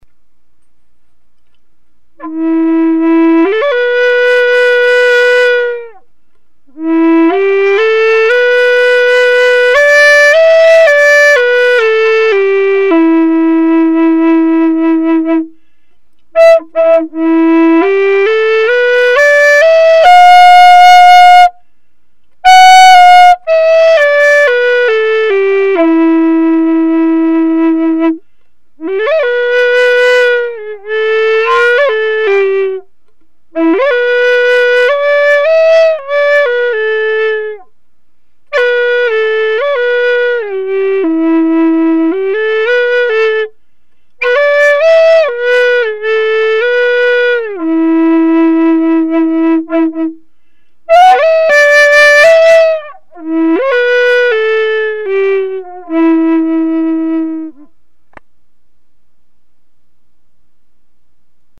Plains-Style Native American Flute in E Minor
This flute is made with poplar and accented in red aromatic cedar. It is concert tuned to the pentatonic scale at 440 Hertz.
Finger spacing:   E minor